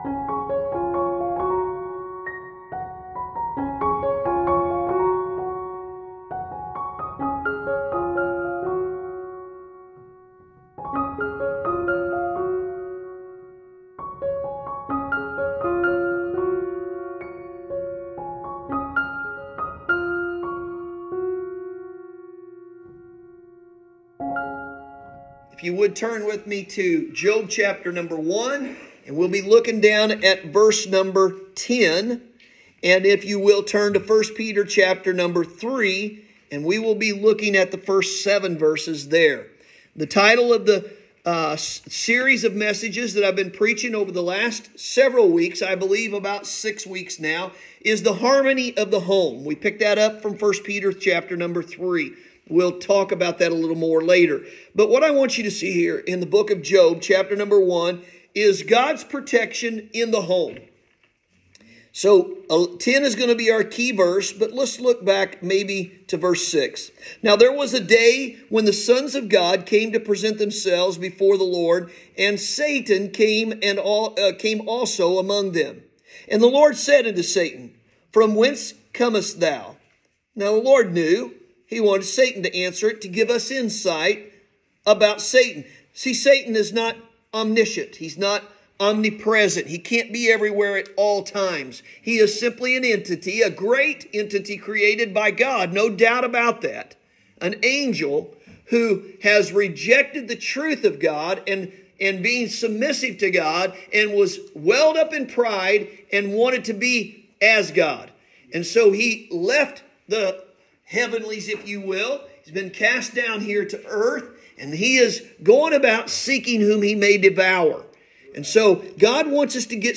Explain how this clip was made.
Sunday Morning – December 11, 2022